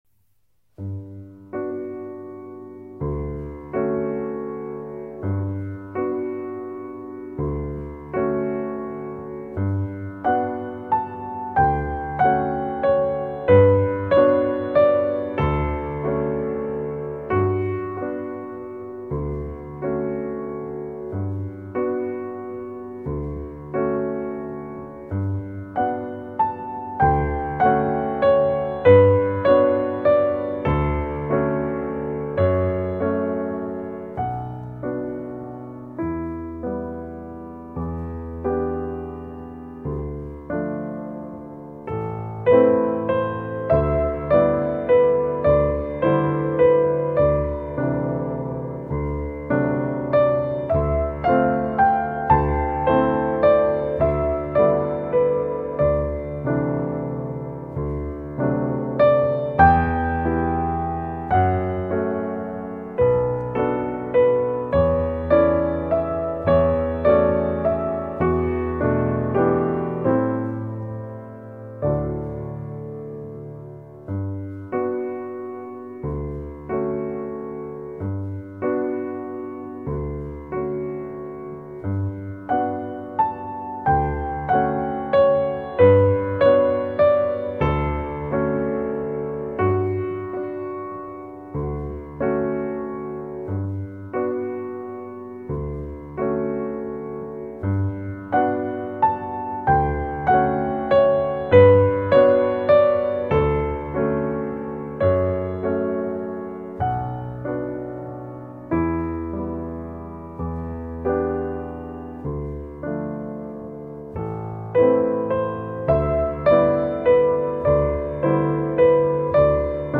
温暖的旋律环绕在身边,仿佛冬日的阳光照在身上 http